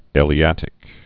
(ĕlē-ătĭk)